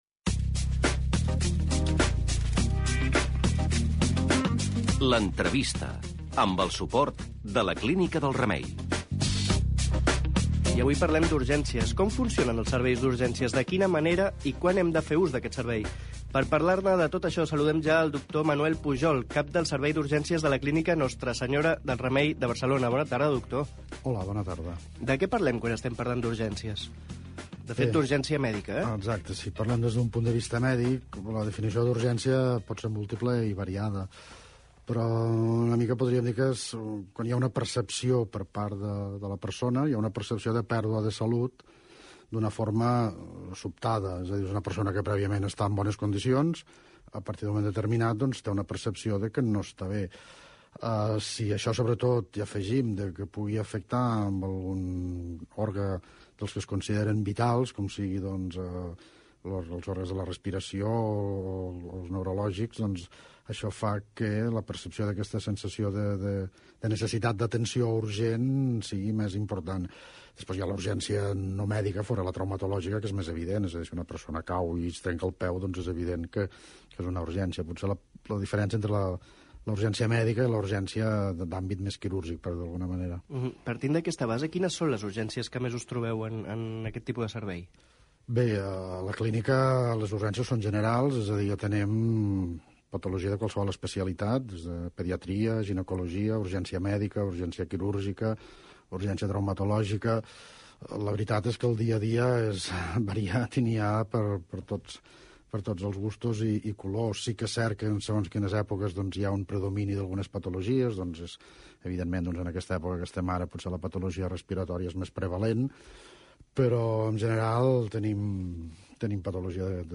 Entrevista
programa Vademecum de Ràdio Estel